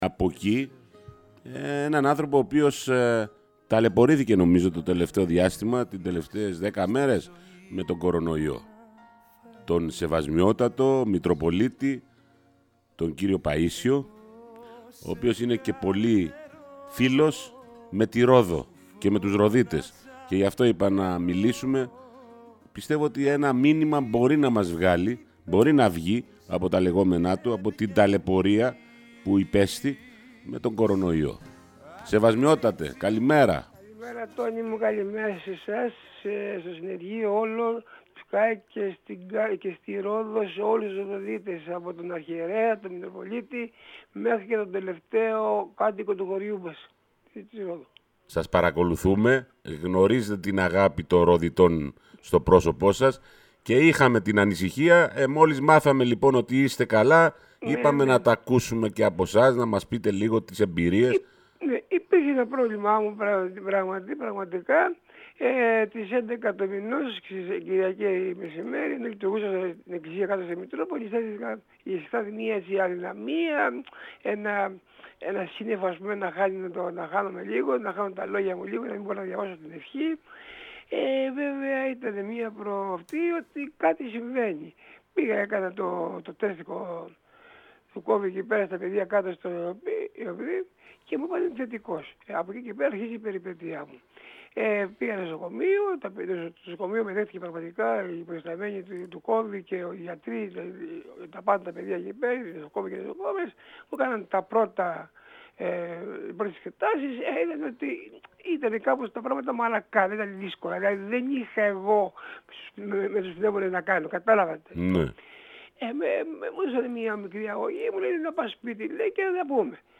Ο ίδιος μιλώντας στον sky για την περιπέτεια της υγείας του με ήπια συμπτώματα και απύρετος, περιέγραψε την εξέλιξη της νόσου από τη στιγμή που βγήκε θετικός, τις εξετάσεις που έκανε και τη φαρμακευτική αγωγή που ακολούθησε στο νοσηλευτικό ίδρυμα και τη χορήγηση οξυγόνου.